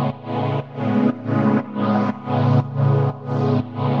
Index of /musicradar/sidechained-samples/120bpm
GnS_Pad-alesis1:4_120-A.wav